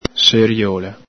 Alto Vic.